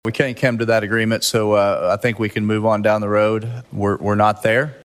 CLICK HERE to listen to commentary from Senate President pro Tem Greg Treat.